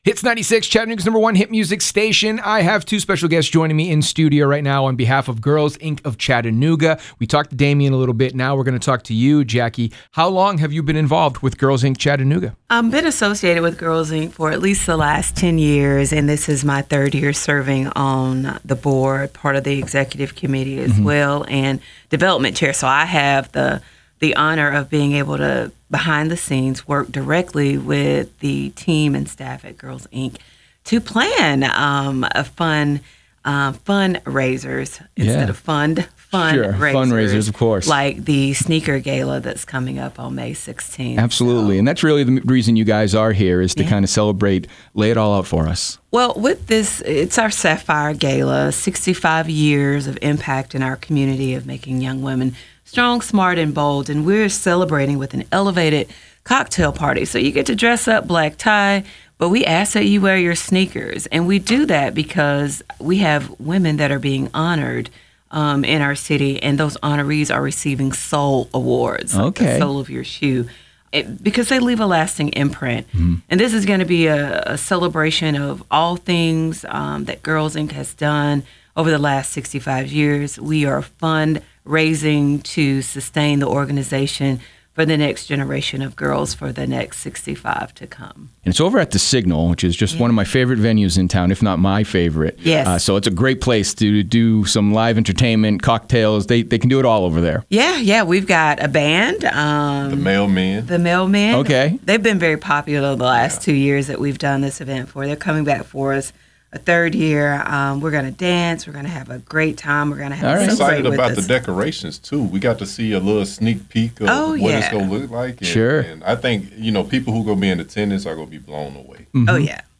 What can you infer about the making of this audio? Segment-2-On-air.wav